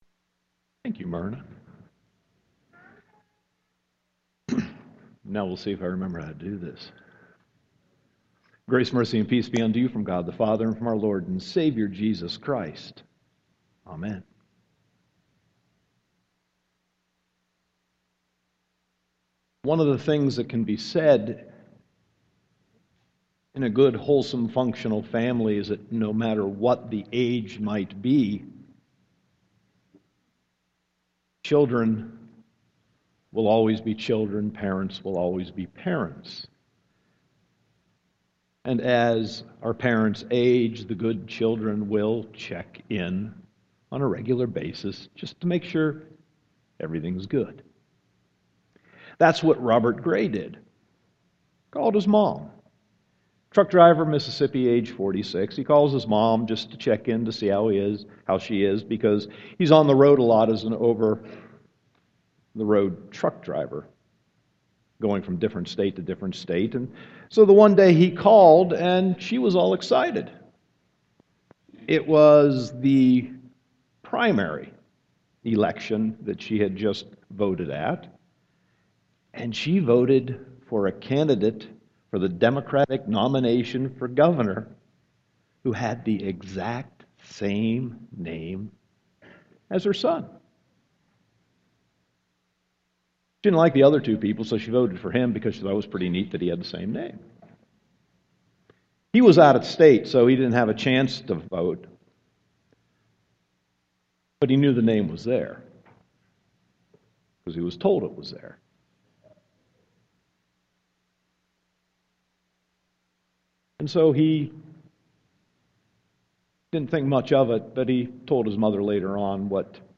Sermon 8.9.2016